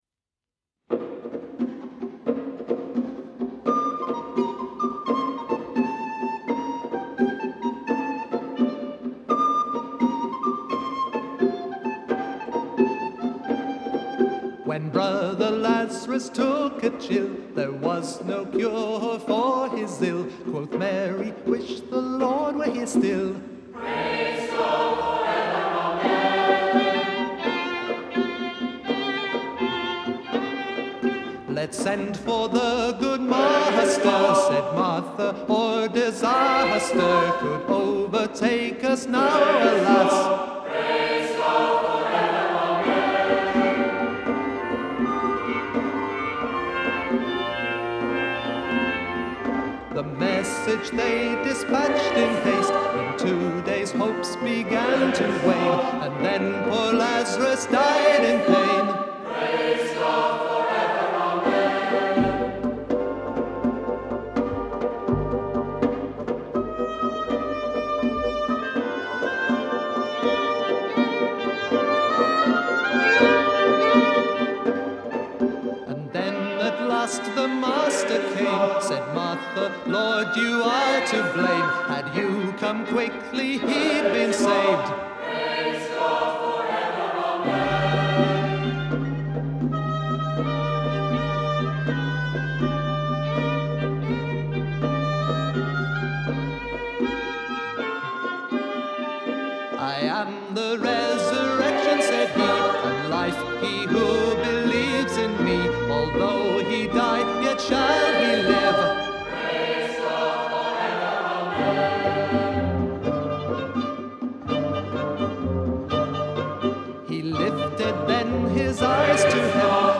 London January 1980.
Music and narration for a mime